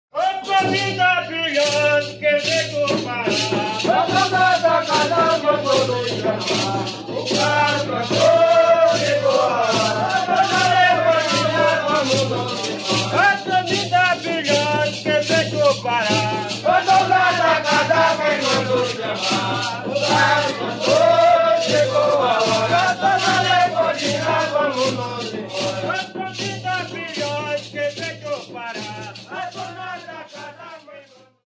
Folguedo afro-brasileiro que tem sua origem nas festas do Rosário.
autor: Cambinda Brilhante de Lucena, data: 1978